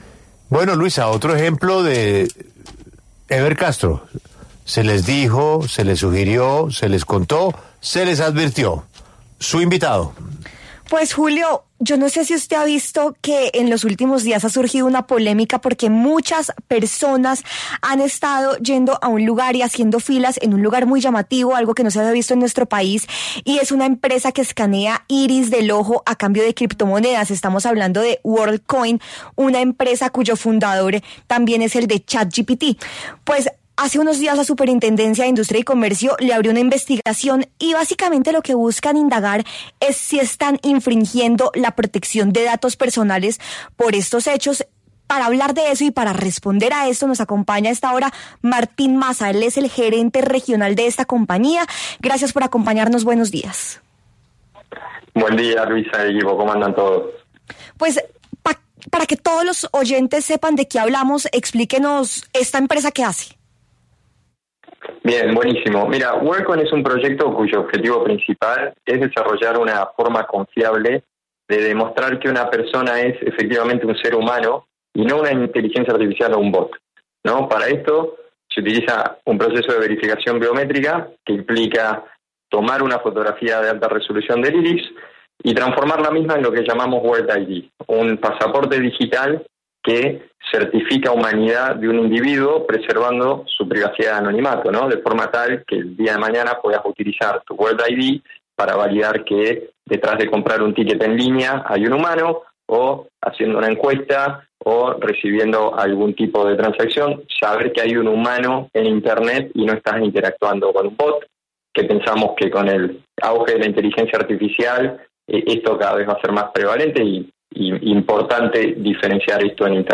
habló en exclusiva para La W